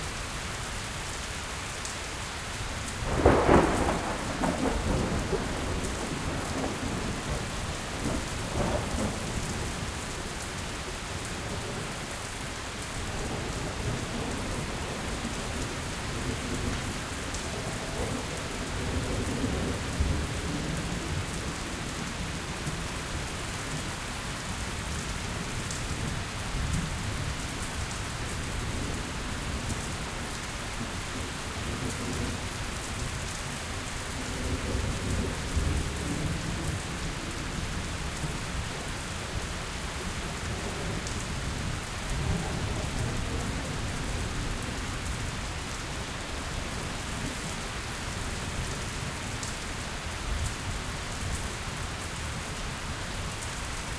panorama-right-rain.ogg